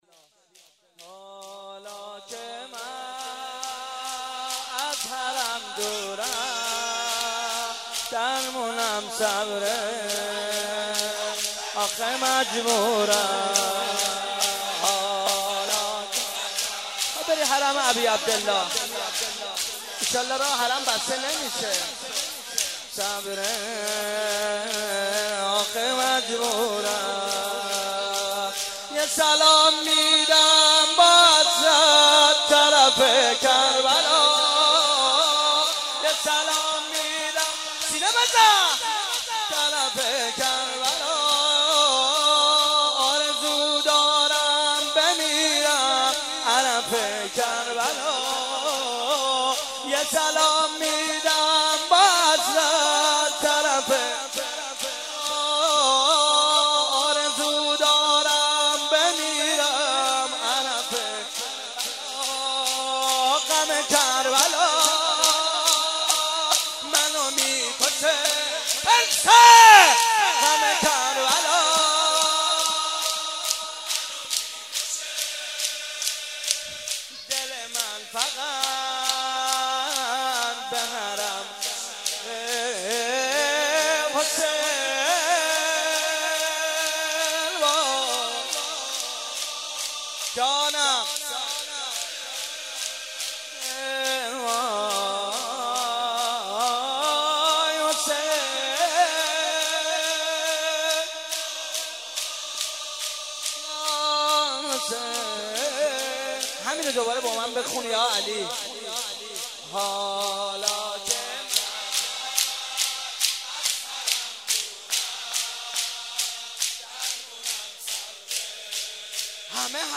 شب سوم رمضان 95، حاح محمدرضا طاهری
04.sineh zani2.mp3